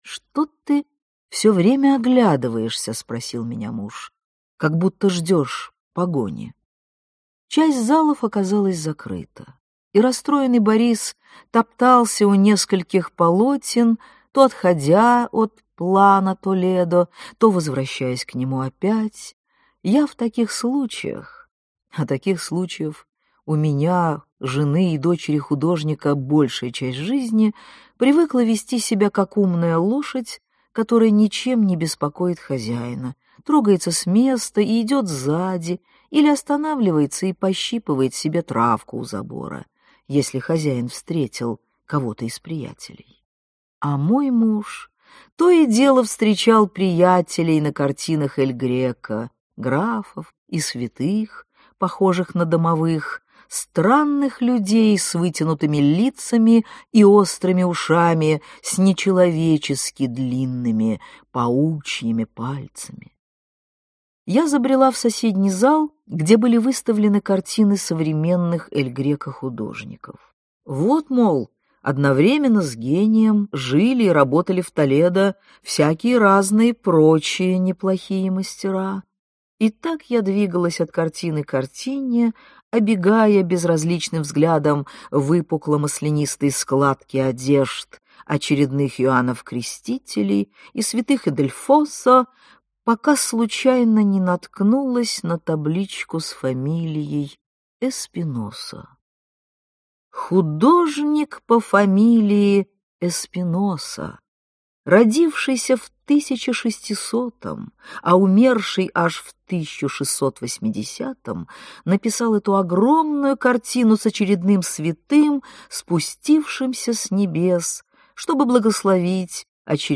Aудиокнига Воскресная месса в Толедо Автор Дина Рубина Читает аудиокнигу Дина Рубина.